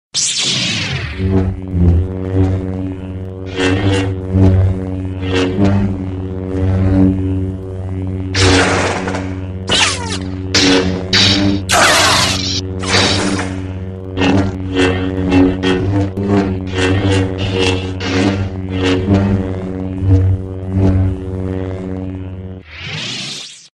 lightsaber-sound-effect-hq-hd.mp3